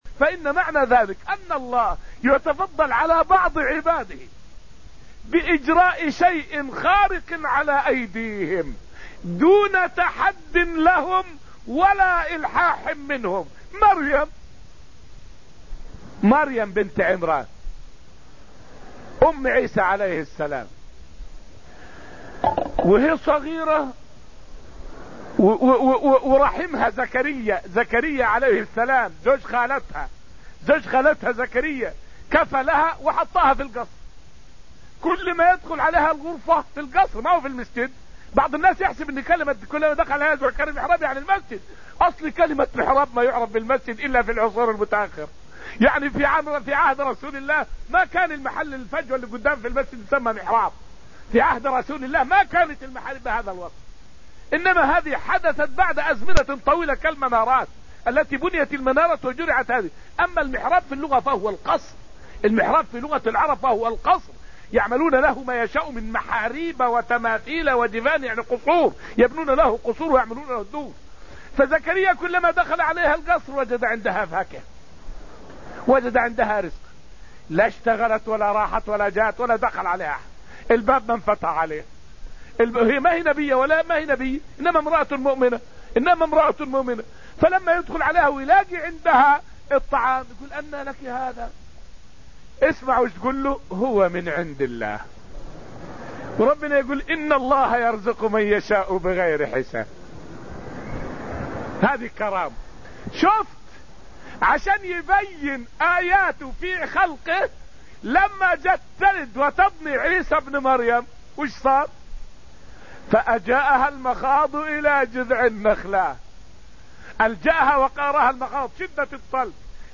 فائدة من الدرس الرابع عشر من دروس تفسير سورة الحديد والتي ألقيت في المسجد النبوي الشريف حول معجزة النبي في رد عين قتادة بن النعمان.